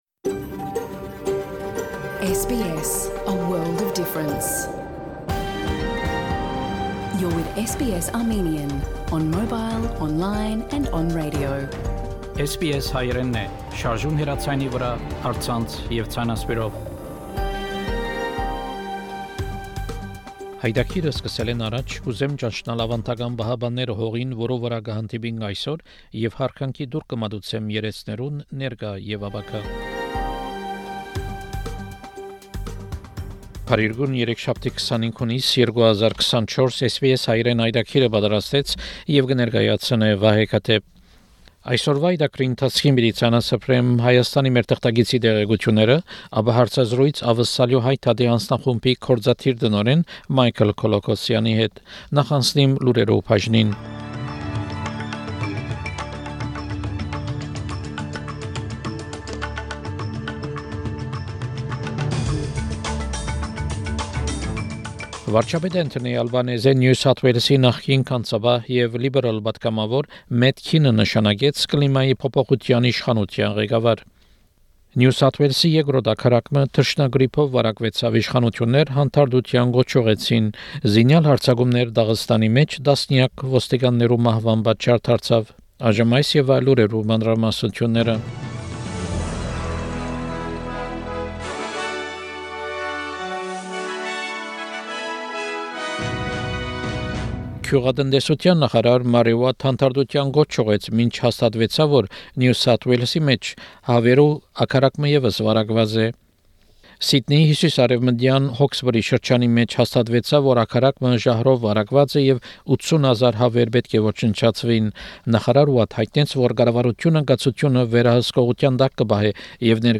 SBS Armenian news bulletin – 25 June 2024
SBS Armenian news bulletin from 25 June program.